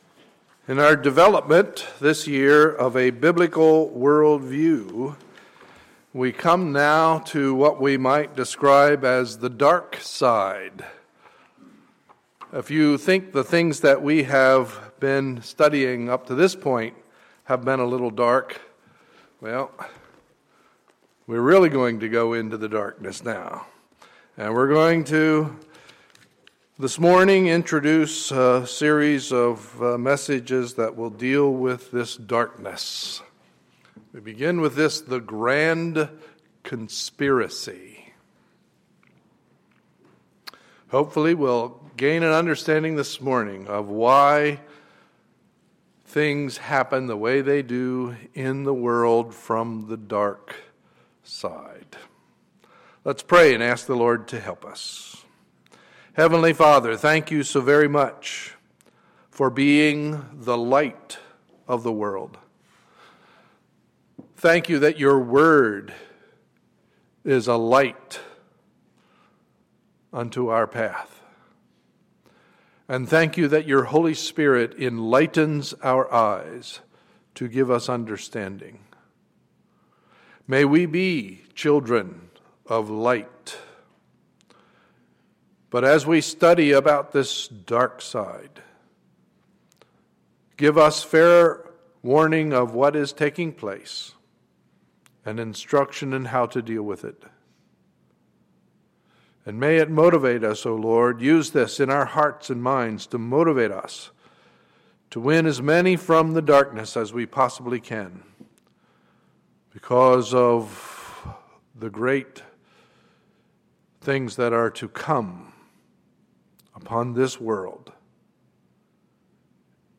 Sunday, July 27, 2014 – Morning Service